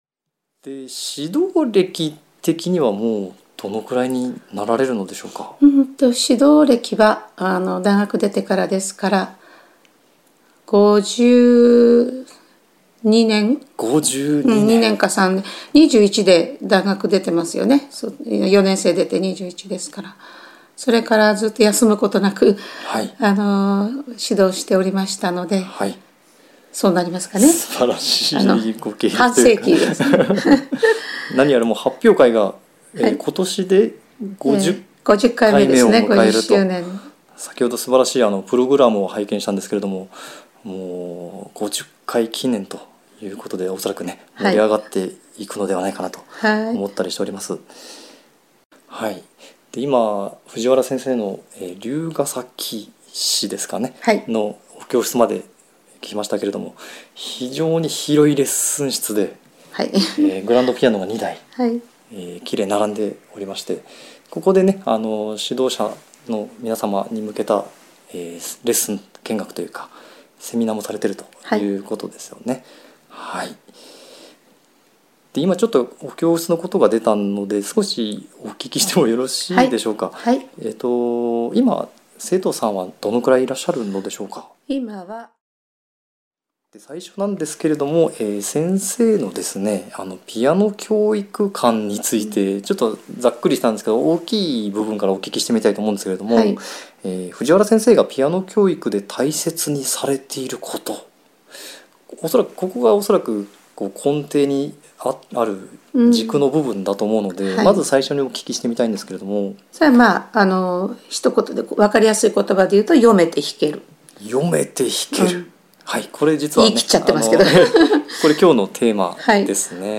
音声は、著名な先生やピアニストとの対談形式。
たとえば、対談はこんな感じです。